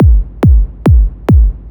K-1 Kick.wav